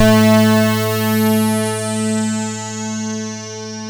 KORG A3  1.wav